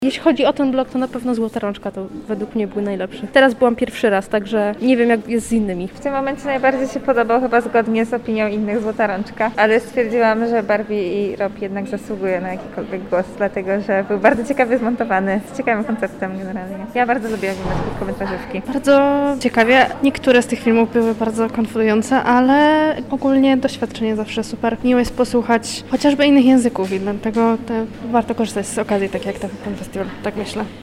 Na premierze „Rodzinnych spraw” czyli serii krótkometrażowych filmów była również nasza reporterka: